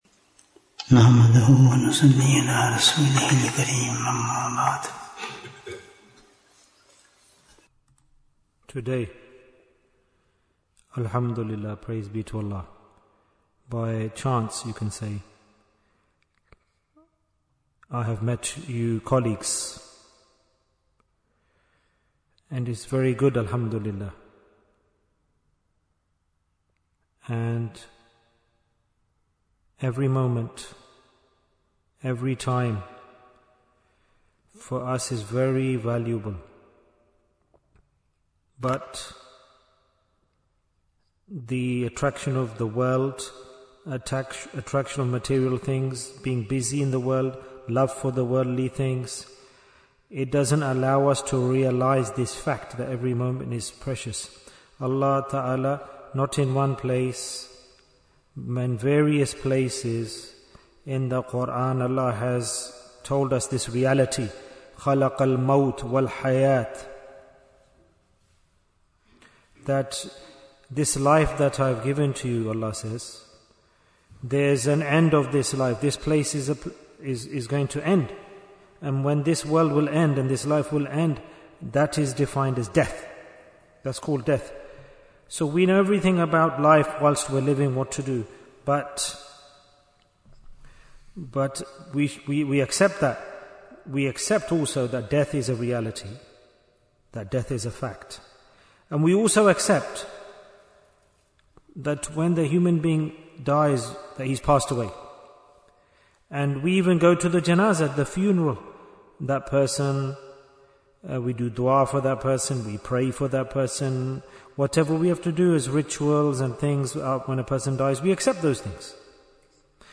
Preparation for Death Bayan, 52 minutes11th November, 2025